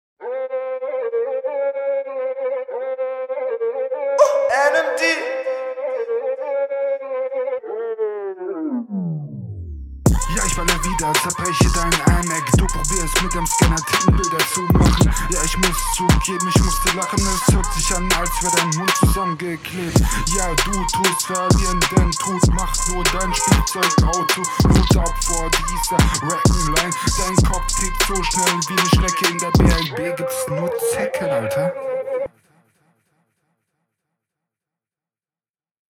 Flow: ➨ Ihr beide flowt zwar unterschiedlich, aber meiner Meinung nach beide nicht so nice. …